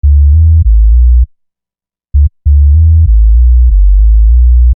Bass 11.wav